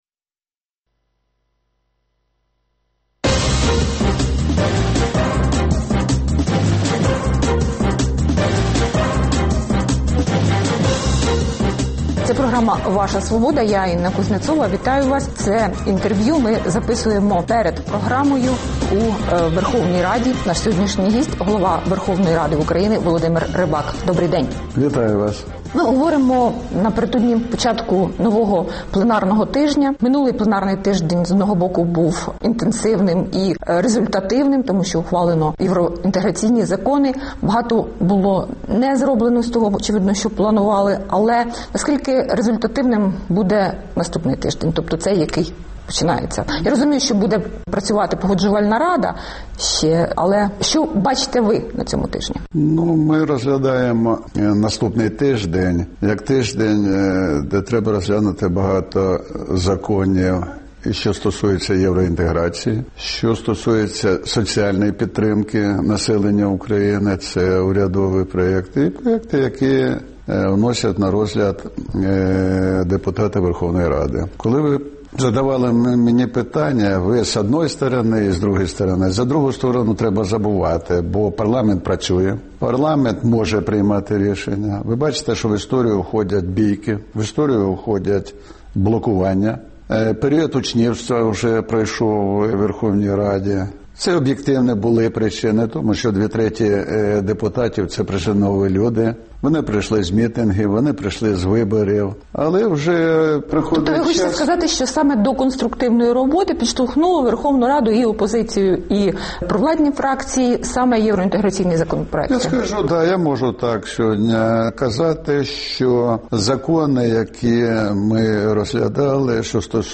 Гість: голова Верховної Ради України Володимир Рибак